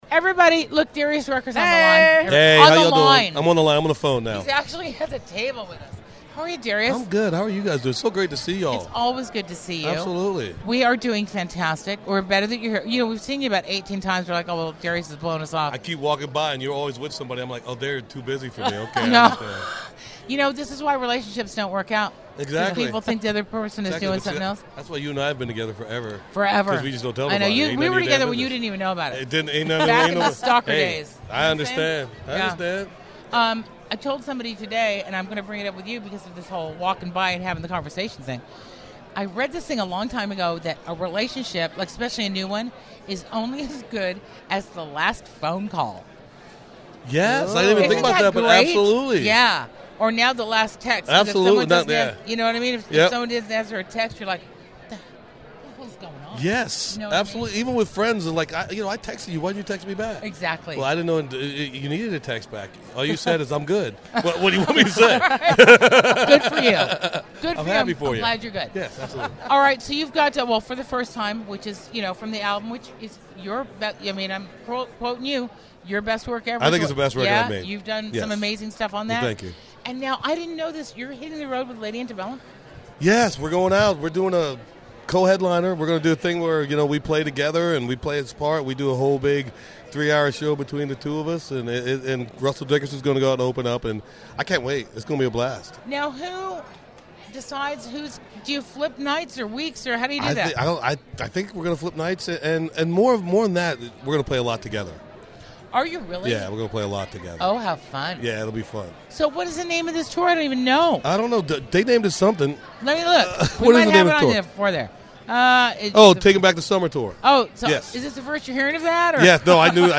Darius Rucker Interview At 2018 ACMs!